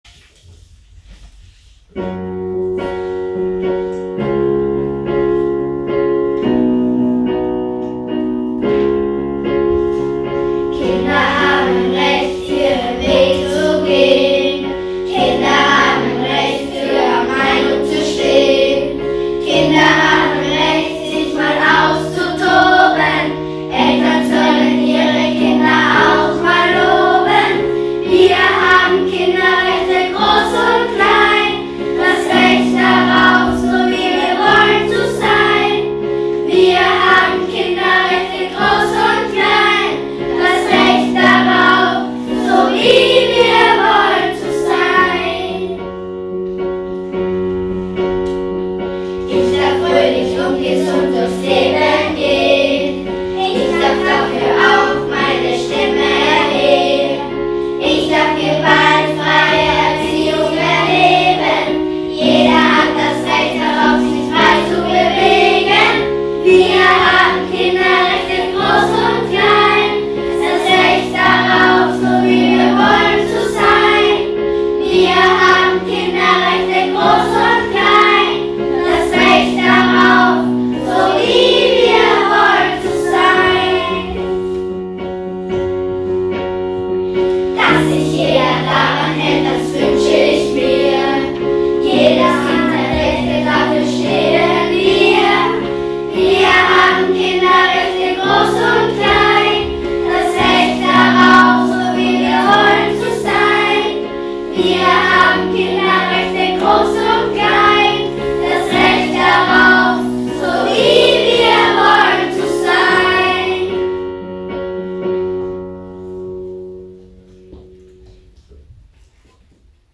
Powergirls und starke Burschen – das ist die 4a-Klasse der VS Frohnleiten.